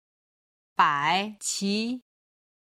今日の振り返り！中国語発音